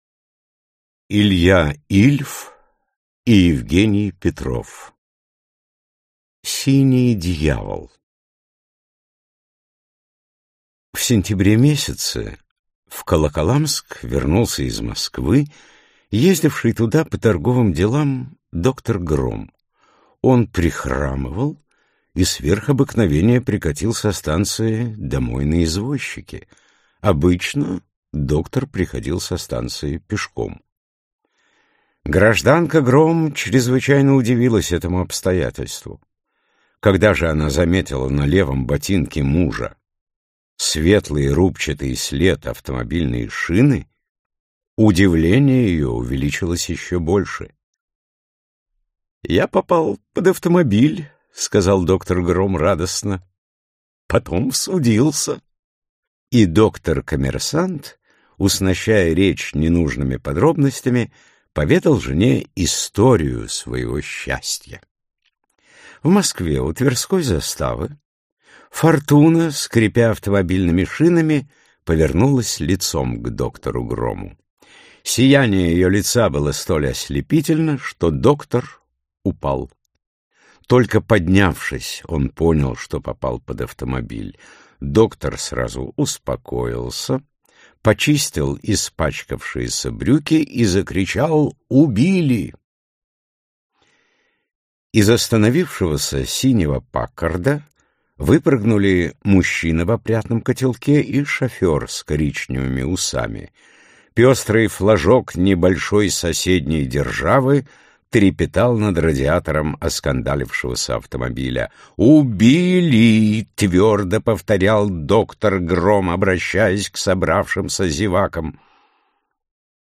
Аудиокнига Юмористы к барьеру | Библиотека аудиокниг